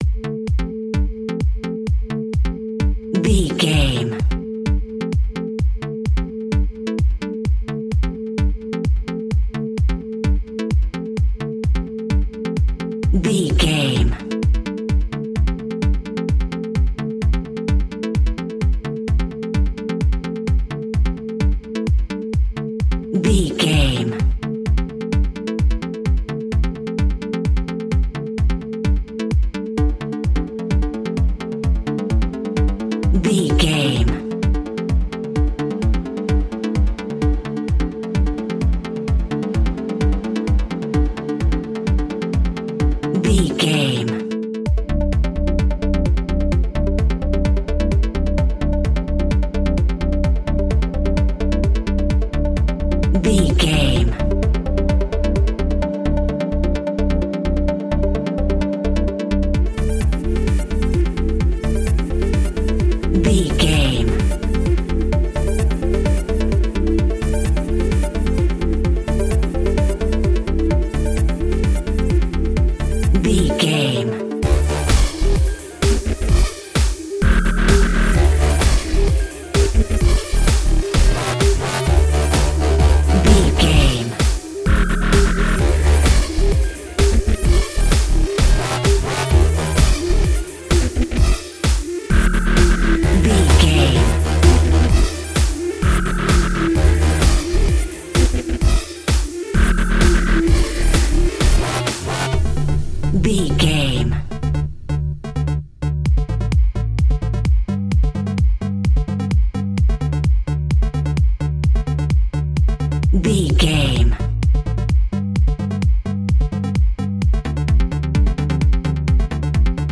Aeolian/Minor
Fast
aggressive
dark
groovy
futuristic
industrial
frantic
drum machine
synthesiser
breakbeat
energetic
synth leads
synth bass